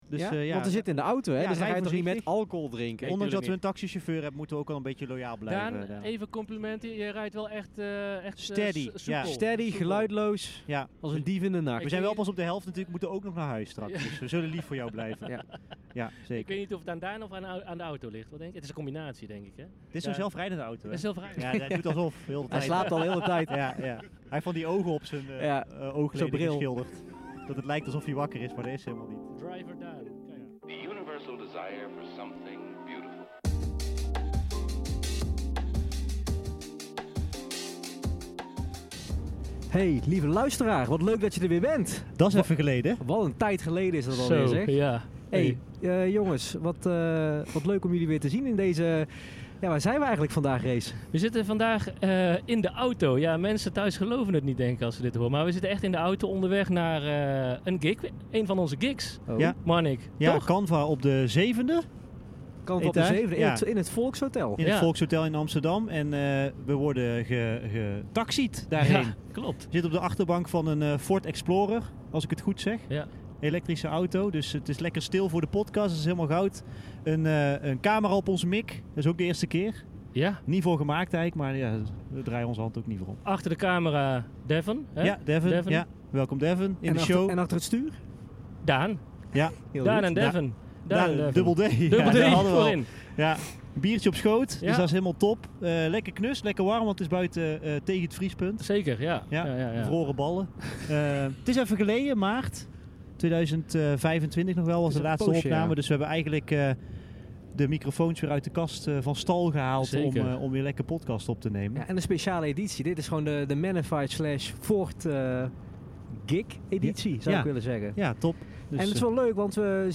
Yes yes, dit keer nemen we je mee vanaf de achterbank van de nieuwe Ford Explorer.